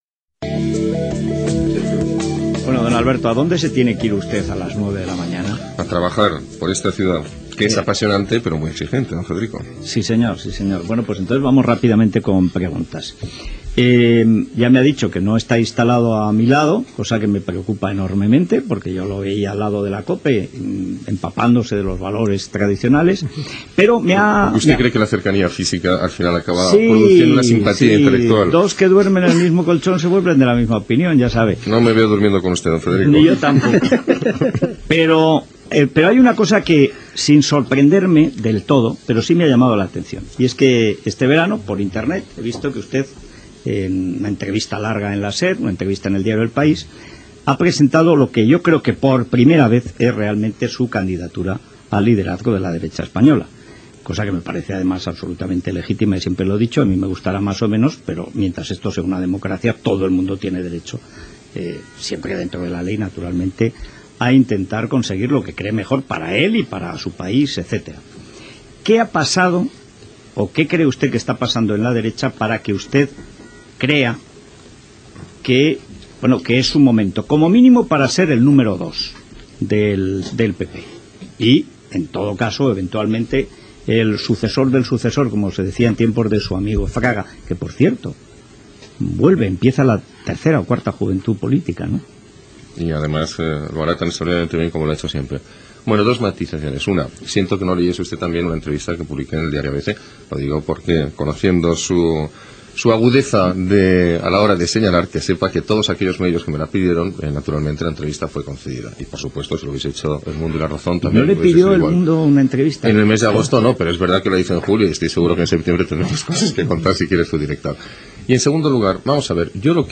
Fragment d'una entrevista al polític Alberto Ruiz Gallardón.
Info-entreteniment
FM